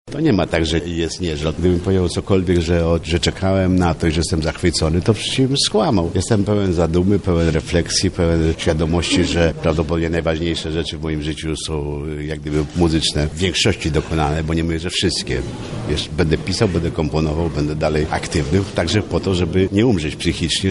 O nastroju przed ostatnim koncertem mówi Romuald Lipko.